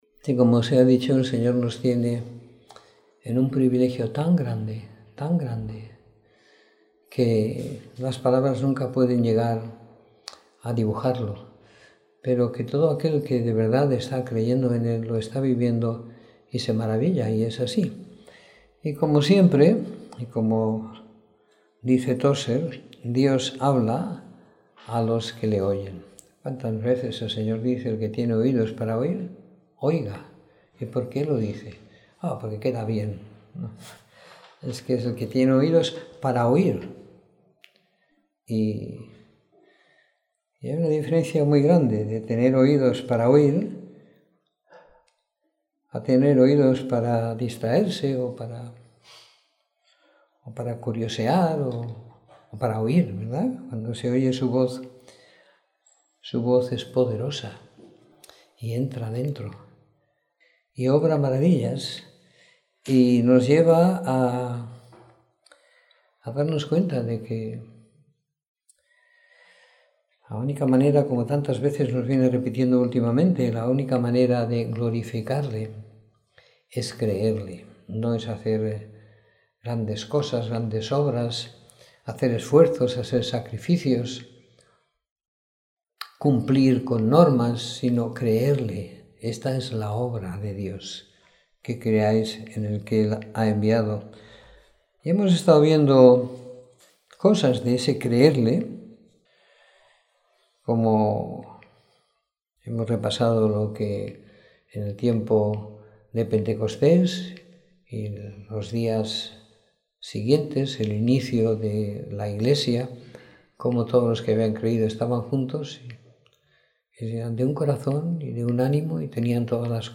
Domingo por la Tarde . 12 de Junio de 2016